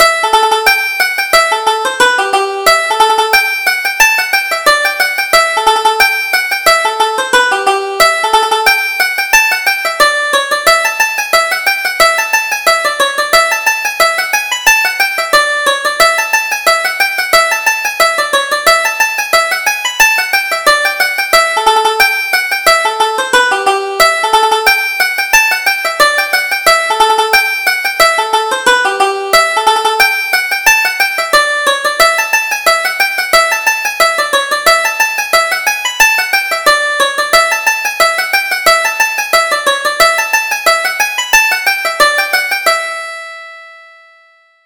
Reel: The Merry Harriers - 1st Setting